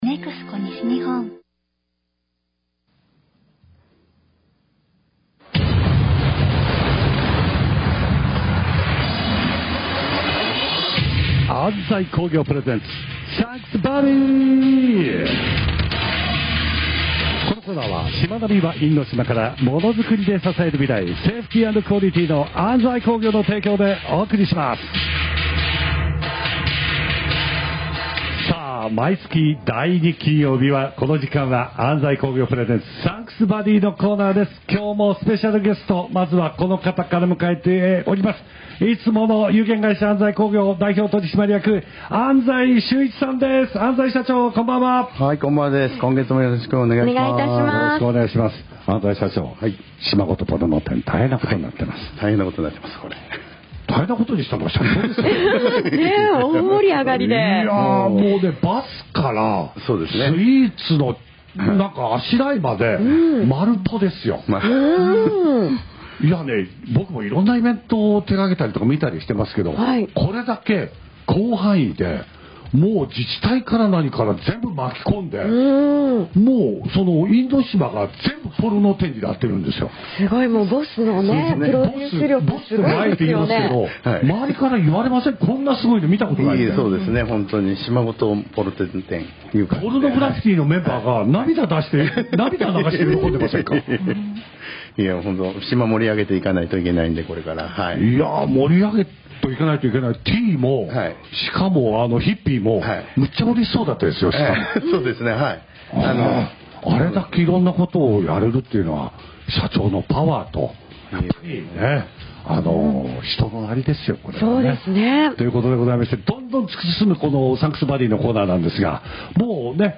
ラジオ放送4回目～♪